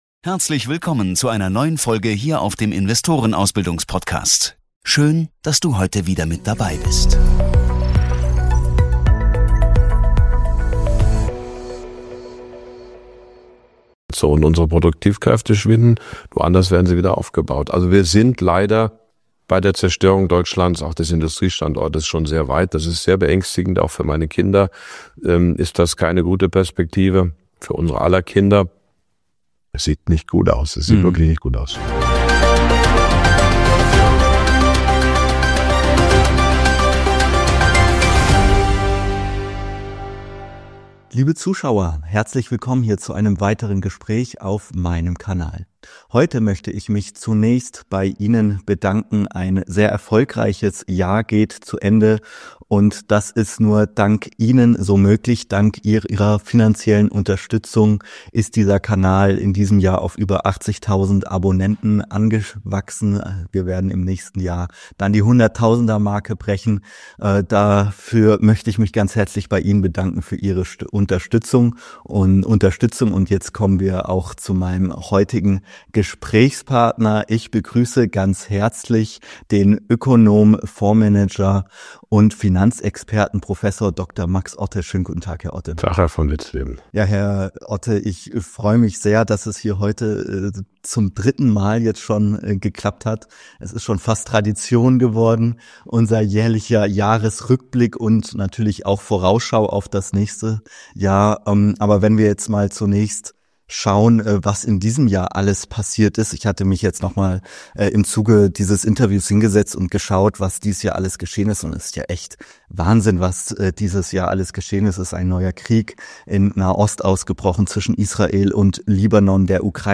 Die beiden diskutieren die deutsche Wirtschaftskrise, geopolitische Veränderungen und die Auswirkungen der US-Präsidentschaftswahl. Ein fundiertes Gespräch über Deutschlands Position in der sich wandelnden Weltordnung.